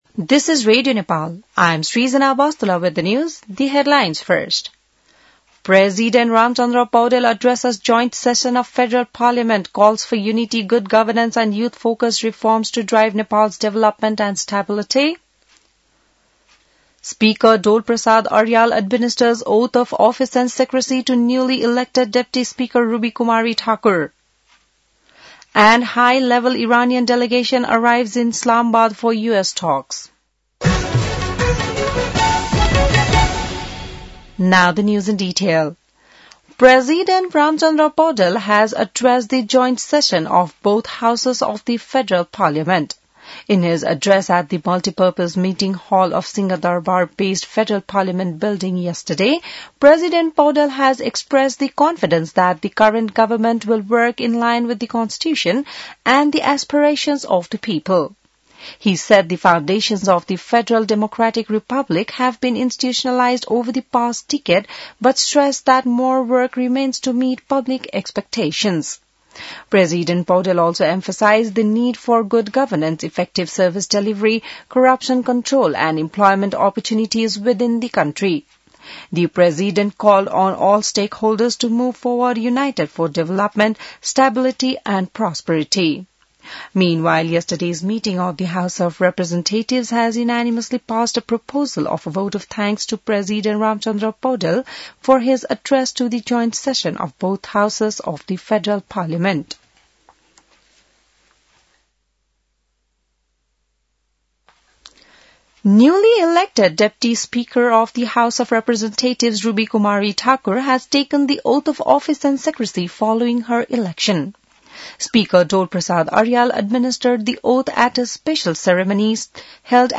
बिहान ८ बजेको अङ्ग्रेजी समाचार : २८ चैत , २०८२